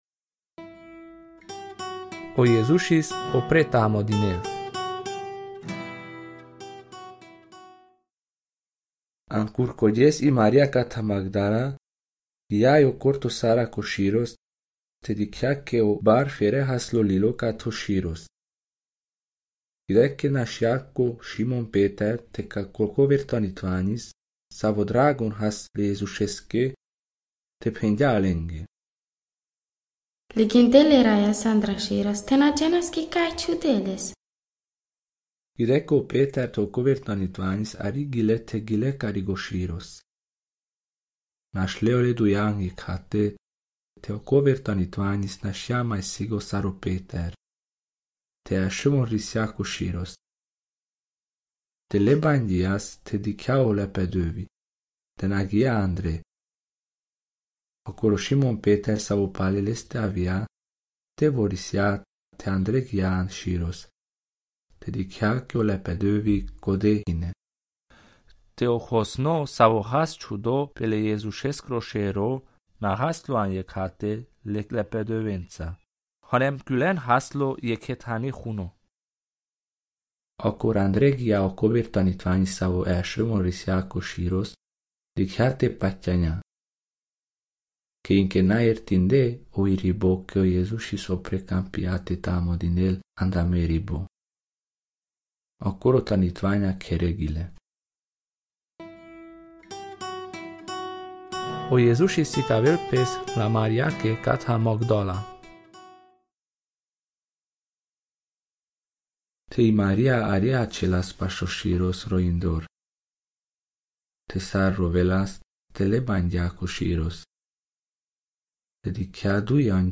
Audio: Dialectul carpatic